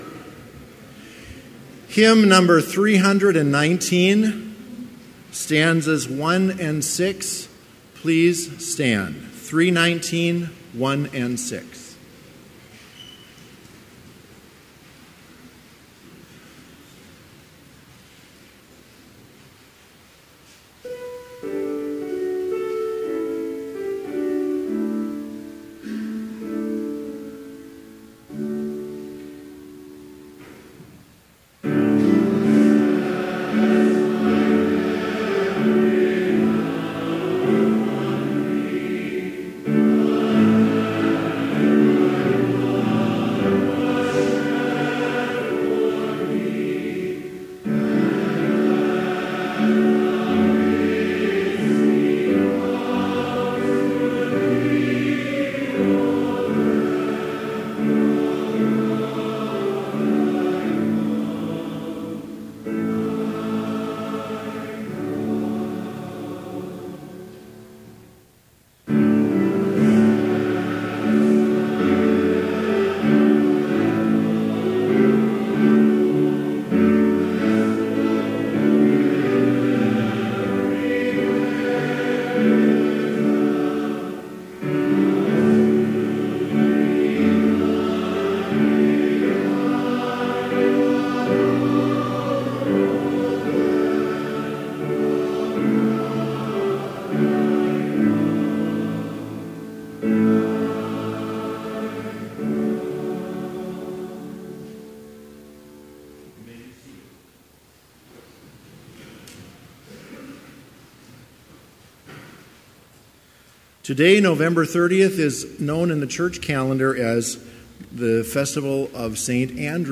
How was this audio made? Complete service audio for Chapel - November 30, 2017